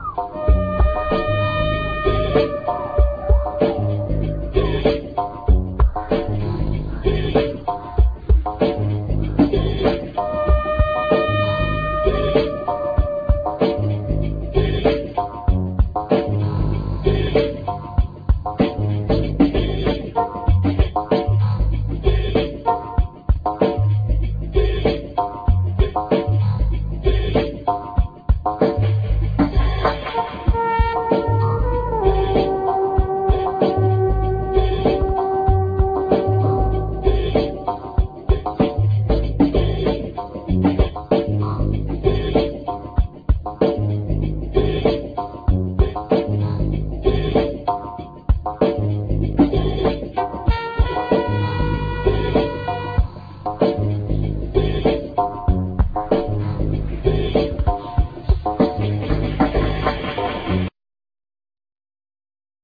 Trumpet
Turntables
Drums
Bass
Fender piano
Synthsizer, Hammond organ
African percussions
Guitar